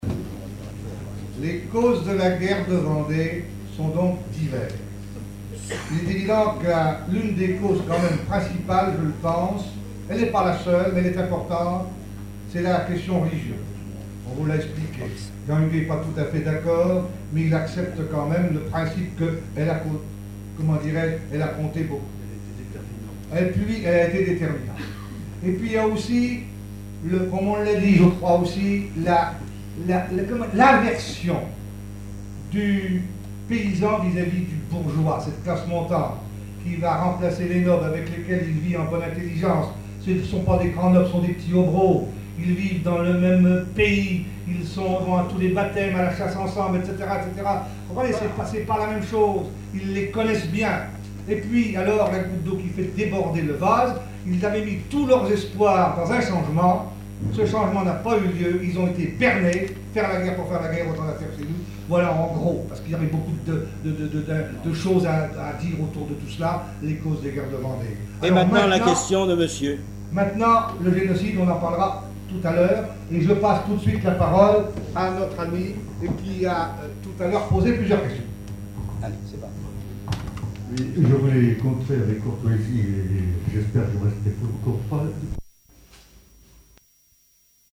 chouannerie, guerre de Vendée ; congrès, colloque, séminaire, conférence
Conférence de la Société des écrivains de Vendée
Catégorie Témoignage